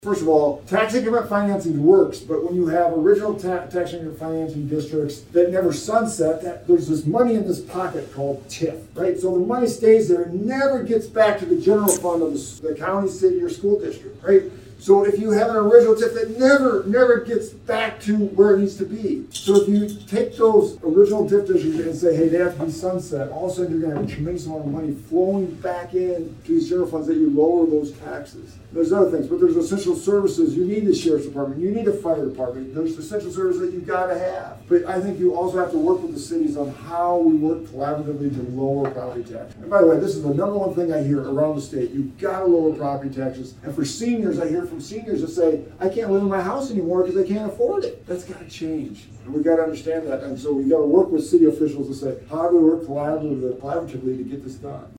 A group gathered inside one of the back dining areas at Pizza Ranch while Feenstra gave his speech on his campaign run.